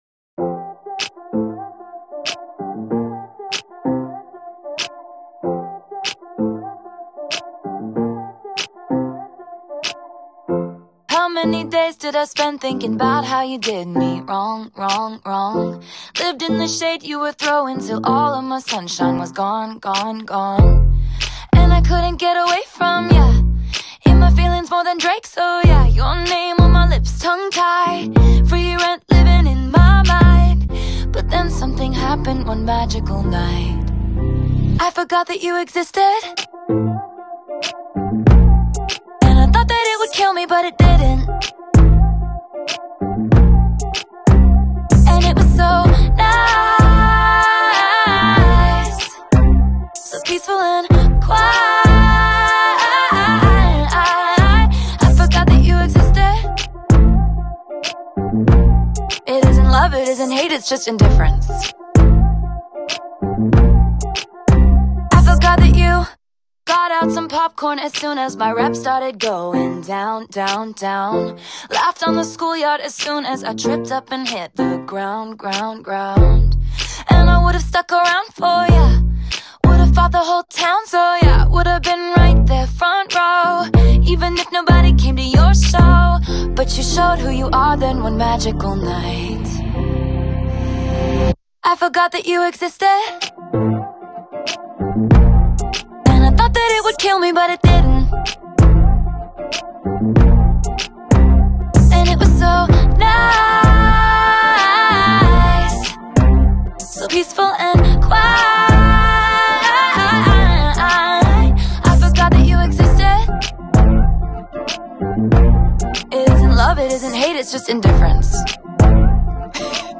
Synth-Pop, Pop, Electropop